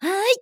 YX蓄力1.wav 0:00.00 0:00.42 YX蓄力1.wav WAV · 37 KB · 單聲道 (1ch) 下载文件 本站所有音效均采用 CC0 授权 ，可免费用于商业与个人项目，无需署名。
人声采集素材